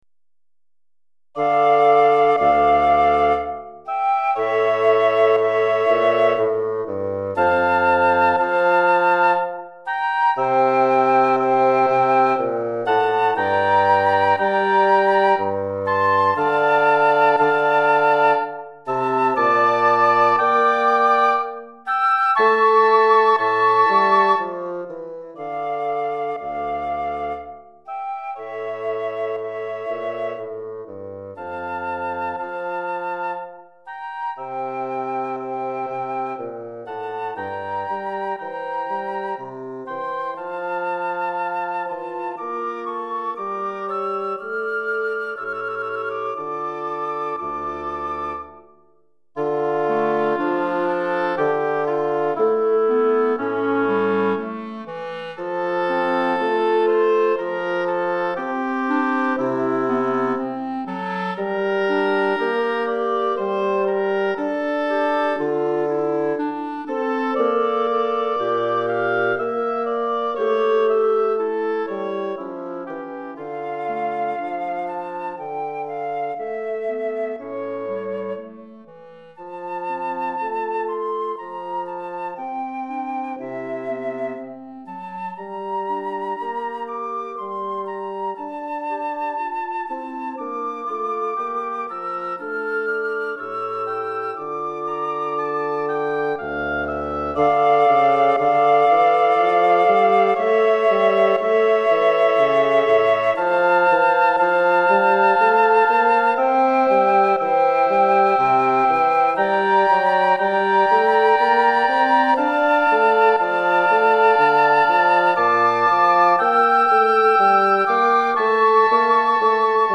Répertoire pour Musique de chambre - Quatuor à Vents